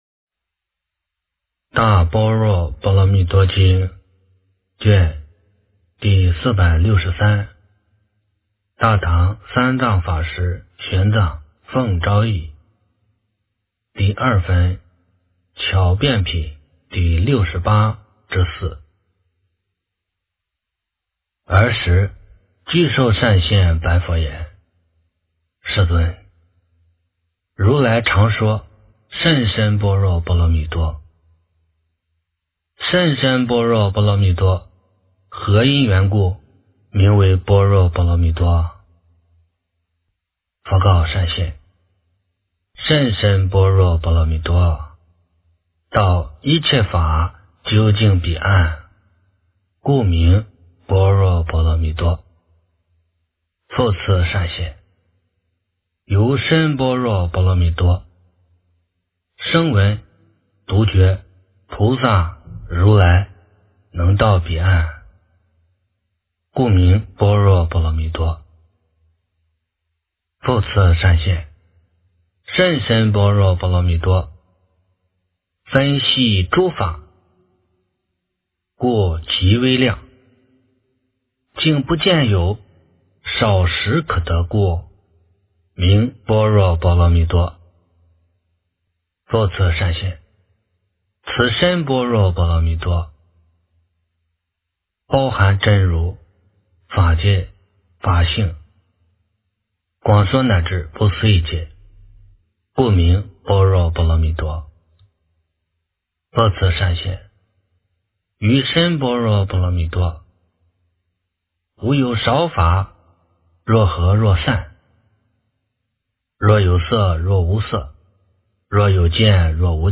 大般若波罗蜜多经第463卷 - 诵经 - 云佛论坛